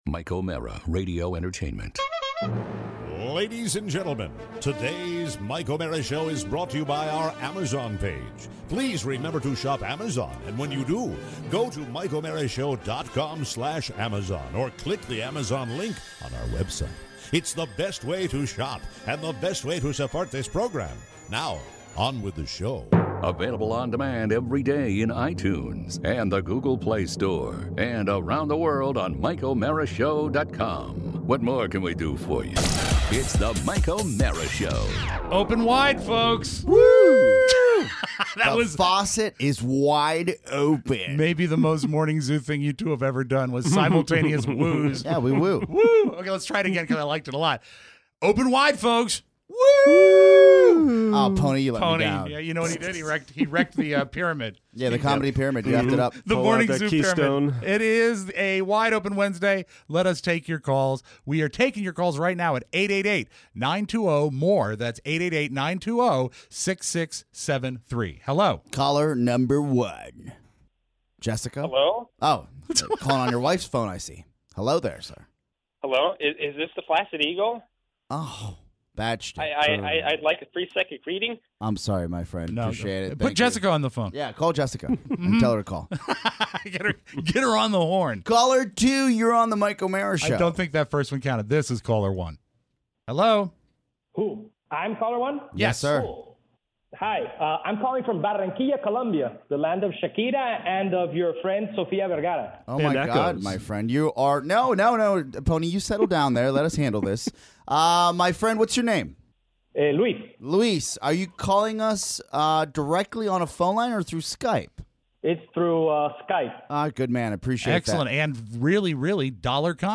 Your calls! Plus a flood update… air conditioner gouging… the sexiest Olympians… too many dogs… and mermaids.